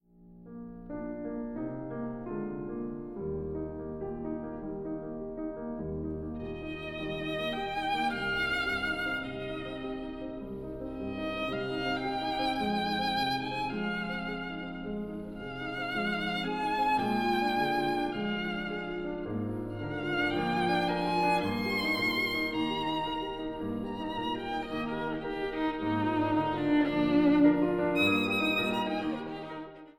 Fassung mit Violine